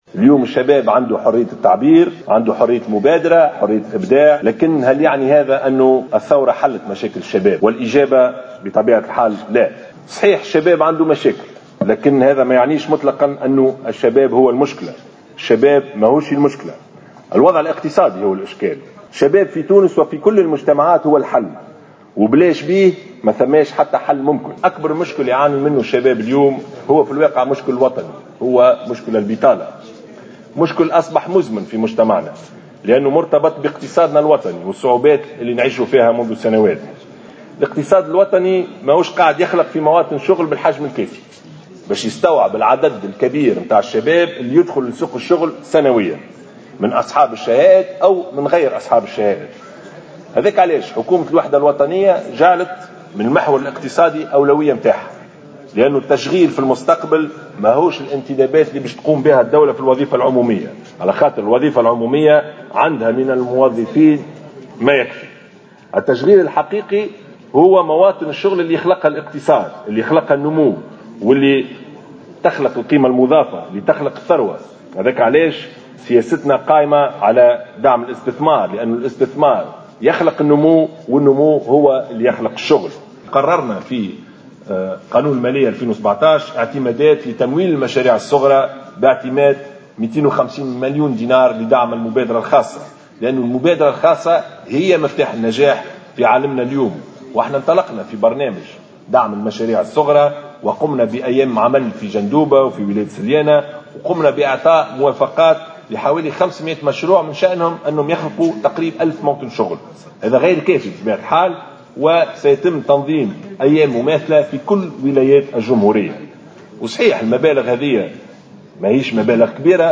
أكد رئيس الحكومة يوسف الشاهد في تصريح لمراسل الجوهرة اف ام اليوم الأربعاء 28 ديسمبر 2016 على هامش اختتام المؤتمر الوطني للشباب الذي انطلقت فعالياته يوم أمس، أن البطالة تعد أكبر معضلة تواجهها الدولة اليوم، وأن الحل لتشغيل الشباب لن يكون عبر الانتداب في الوظيفة العموميّة وإنما عبر بعث مشاريع خاصّة وخلق فرص العمل.